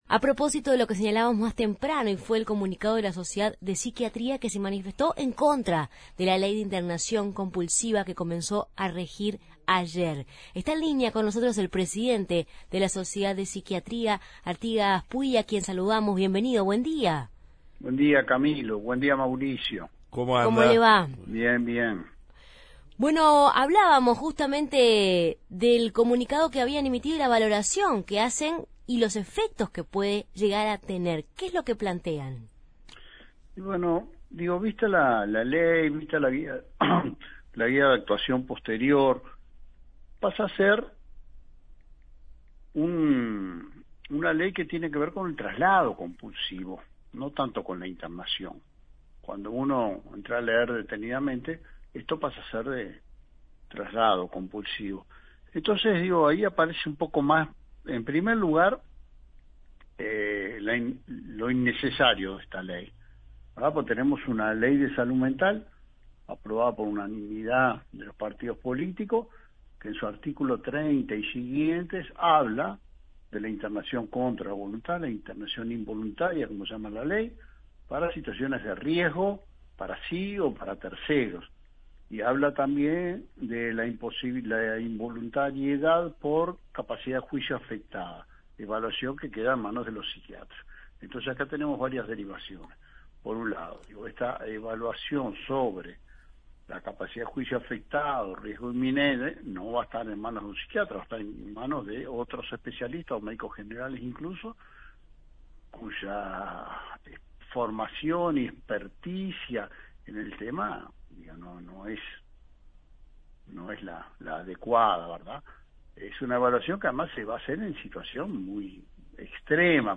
En Justos y pecadores entrevistamos